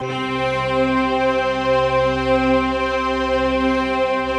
SI1 BELLS07R.wav